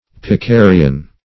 Search Result for " picarian" : The Collaborative International Dictionary of English v.0.48: Picarian \Pi*ca"ri*an\, a. (Zool.)